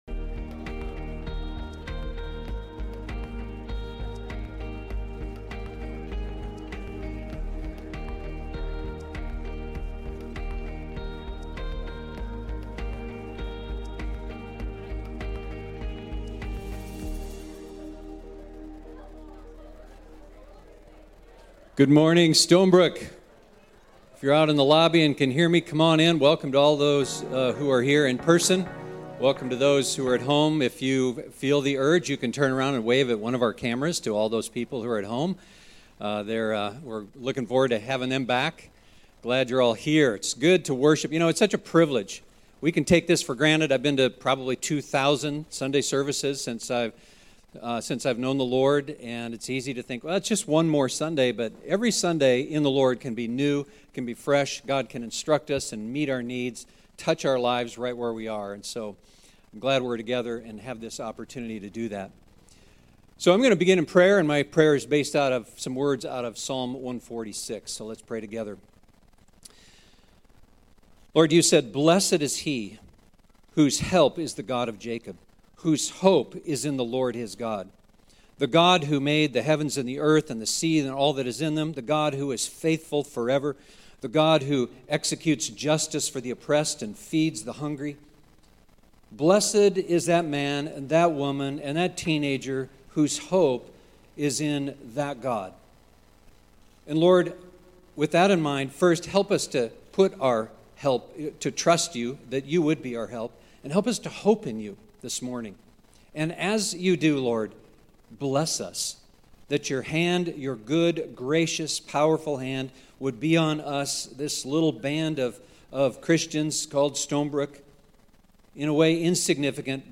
2021 Stay up to date with “ Stonebrook Church Sermons Podcast ”